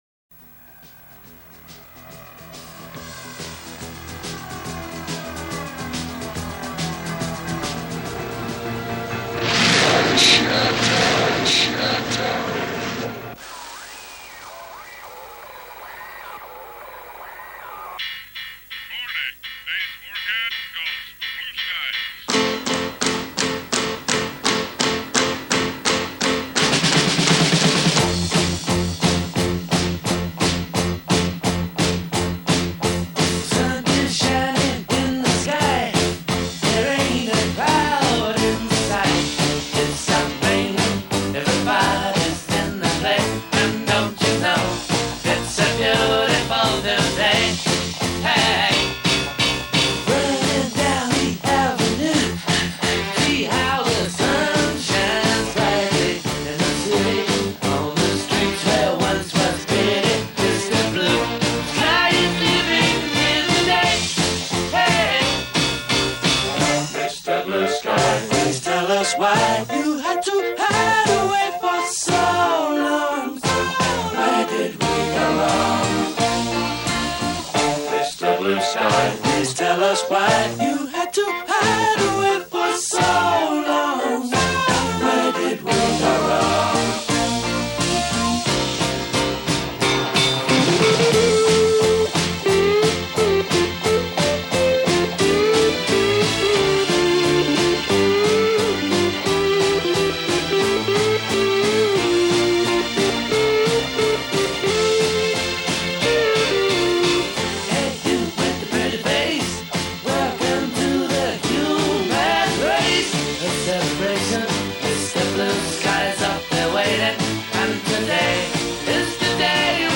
Soundtrack, Pop, Rock